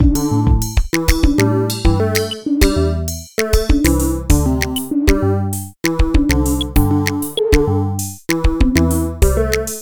Interphase creates continually-evolving music, largely based on pattern generator algorithms that have been optimized per-instrument. The sound palette comes from a library of hand-selected and normalized audio samples, organized by instrument type: kick, snare, hihats, precussion, sound effects, bass, synth, lead. These audio samples were pulled from a few hours of Interphase running and evolving without any user interaction.